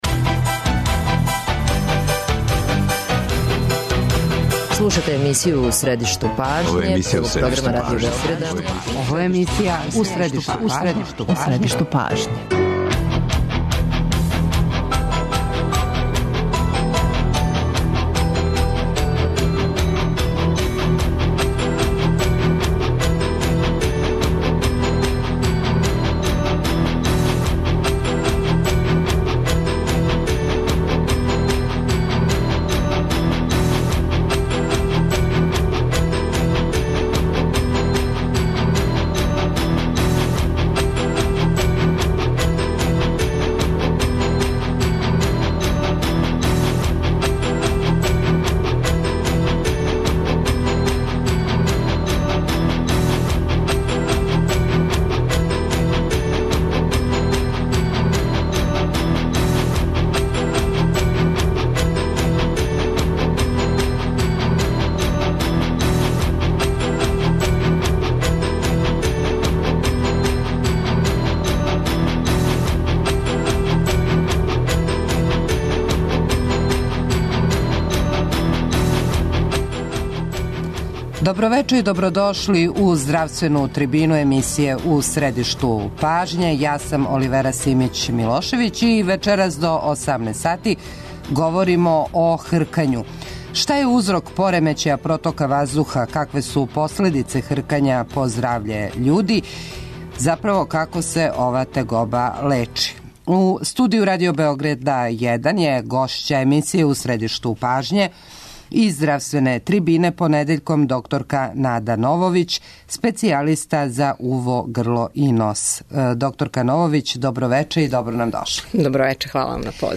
Радио Београд 1, 17.05